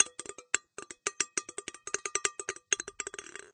plastic_ball_bouncing_in_glass.ogg